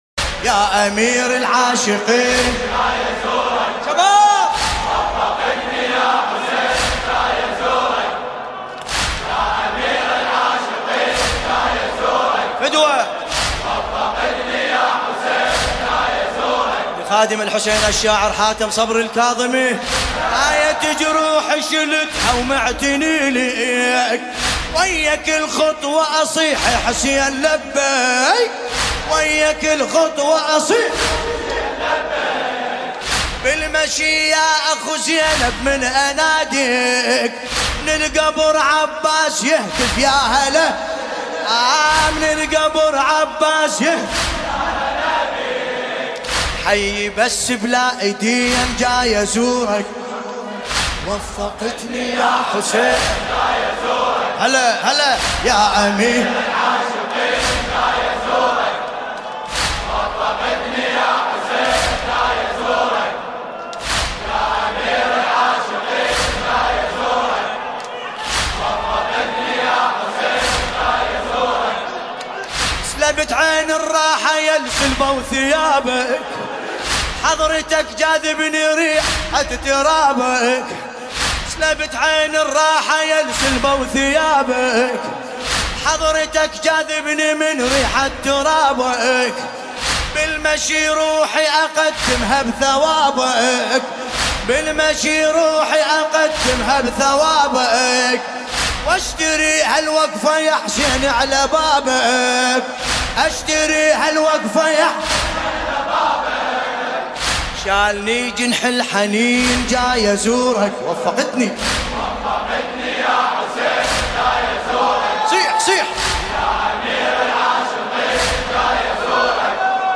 مداحی مذهبی